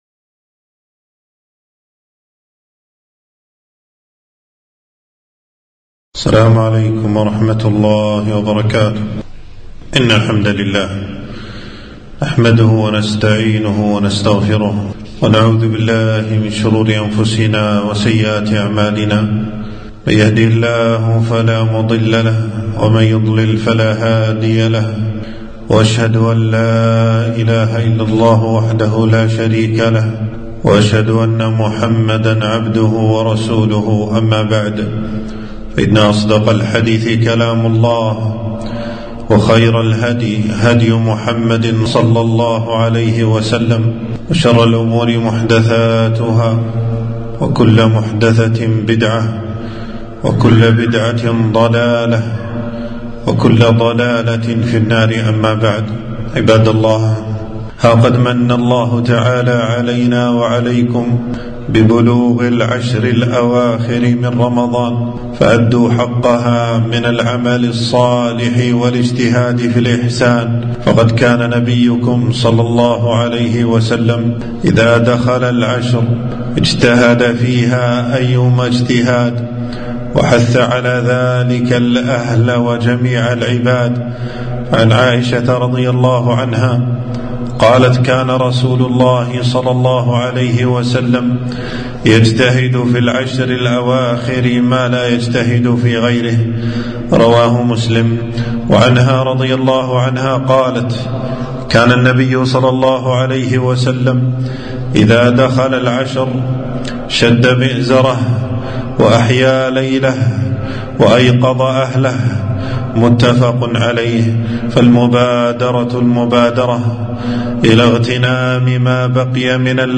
خطبة - ليلة القدر من حرِمها حرِم الخير كله